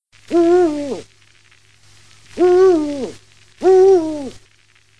Hibou grand-duc
Bubo bubo